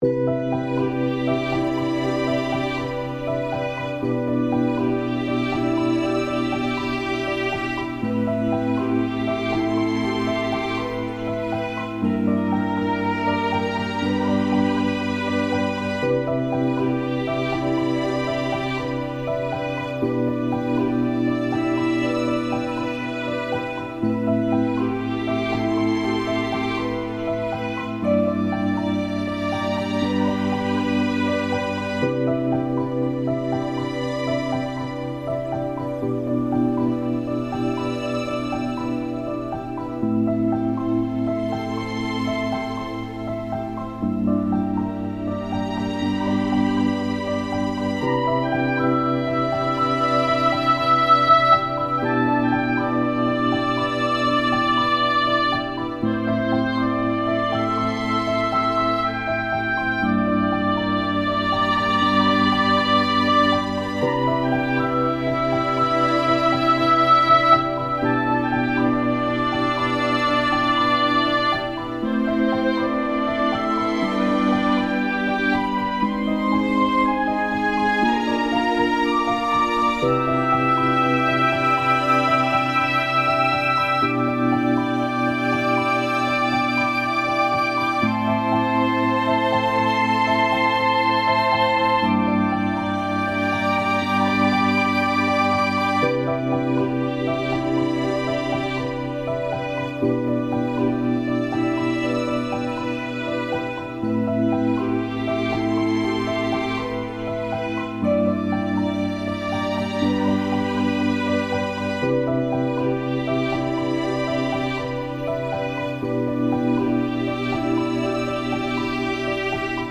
ふわ〜っとした優しい曲です。